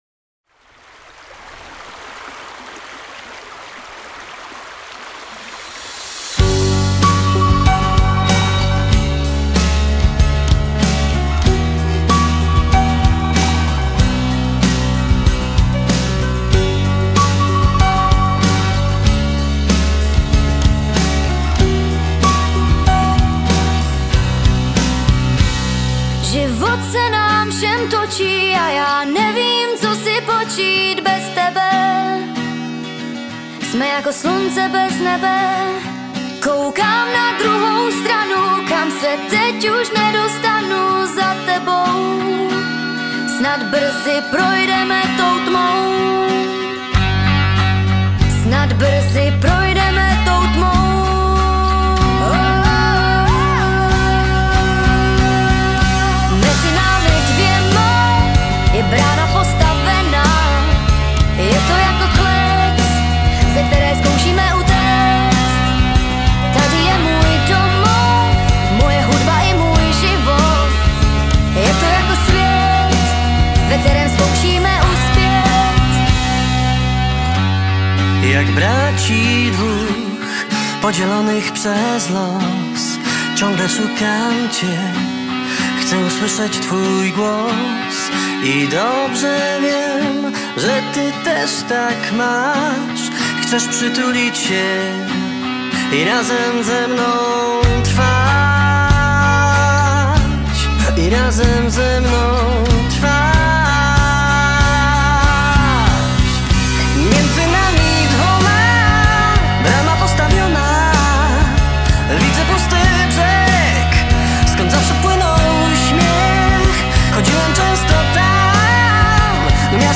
Ukázka z knihy
neni-more-jako-more-audiokniha